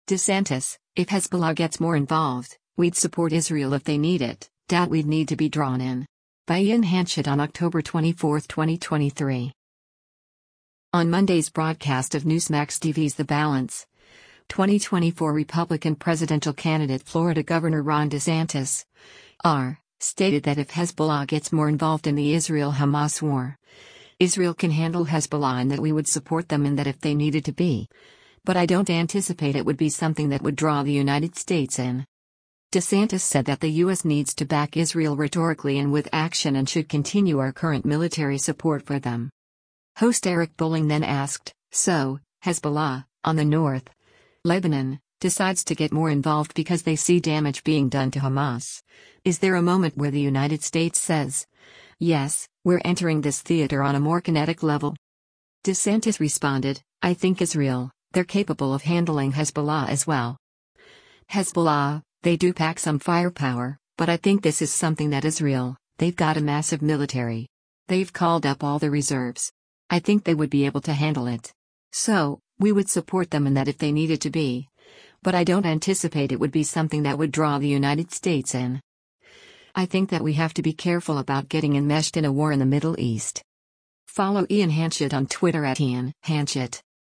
On Monday’s broadcast of Newsmax TV’s “The Balance,” 2024 Republican presidential candidate Florida Gov. Ron DeSantis (R) stated that if Hezbollah gets more involved in the Israel-Hamas war, Israel can handle Hezbollah and that “we would support them in that if they needed to be, but I don’t anticipate it would be something that would draw the United States in.”
Host Eric Bolling then asked, “So, Hezbollah, on the north, Lebanon, decides to get more involved because they see damage being done to Hamas…is there a moment where the United States says, yes, we’re entering this theater on a more kinetic level?”